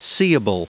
Prononciation du mot seeable en anglais (fichier audio)
Prononciation du mot : seeable